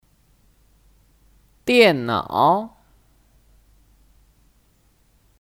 电脑 (Diànnǎo 电脑)